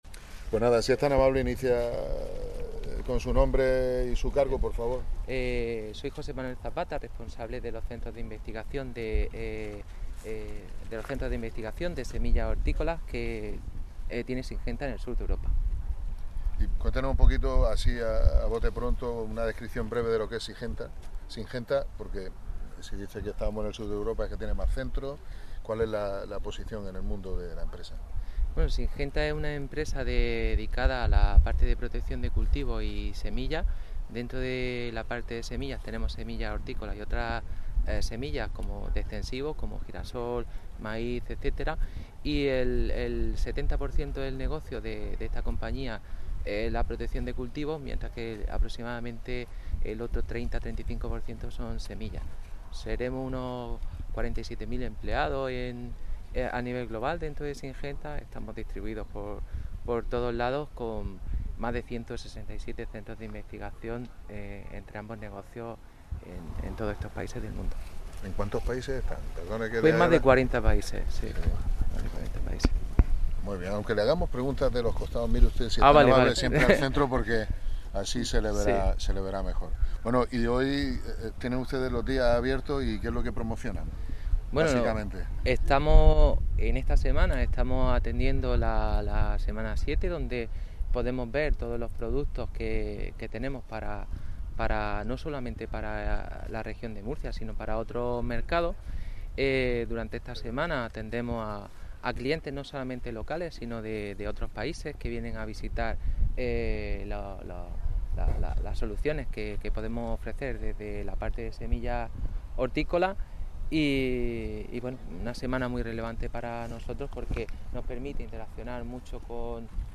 Enlace a Declaraciones de la alcaldesa